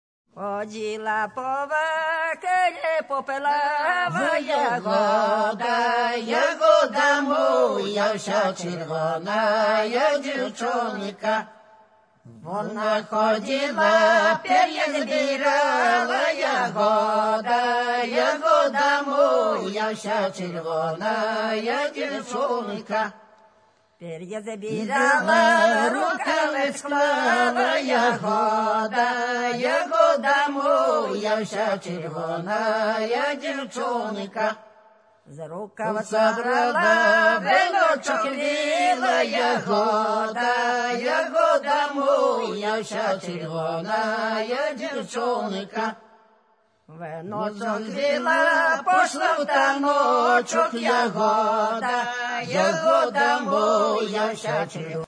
Authentic Performing